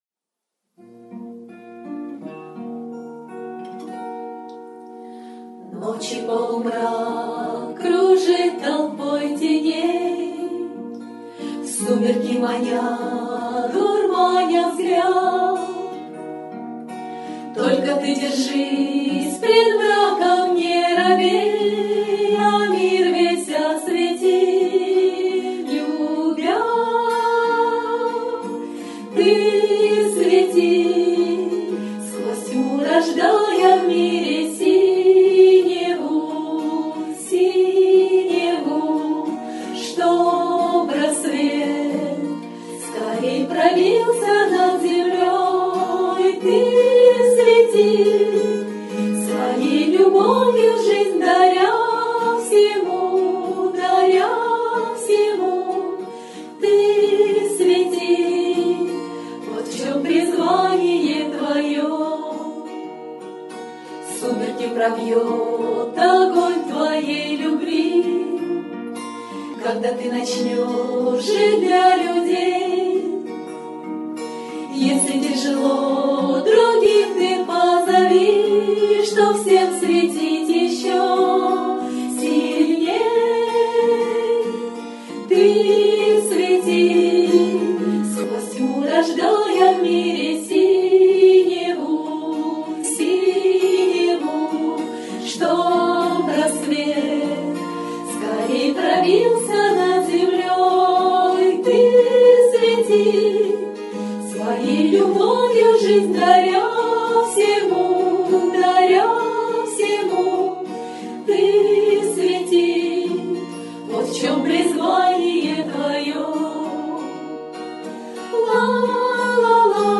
кавер-версия на мотив песни
Для Медитаций